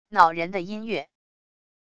恼人的音乐wav音频